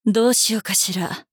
大人女性│女魔導師│リアクションボイス│商用利用可 フリーボイス素材 - freevoice4creators